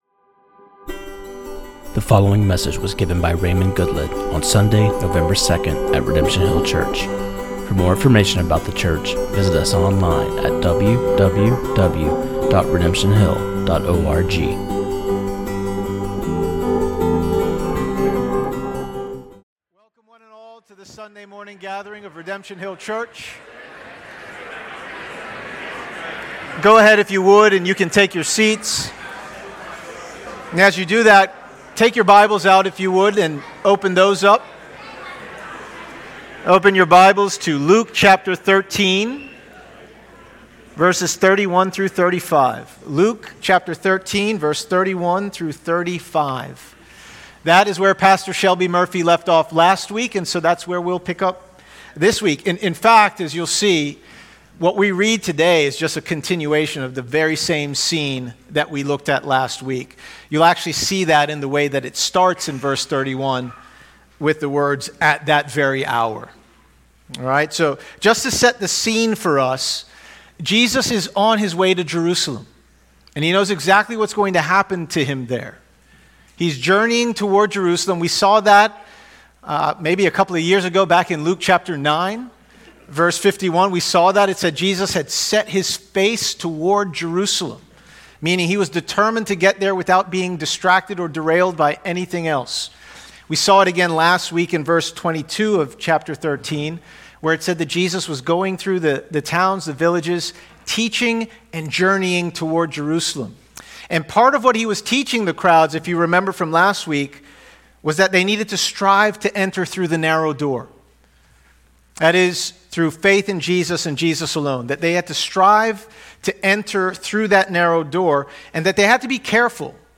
This sermon on Luke 13:31-35 was preached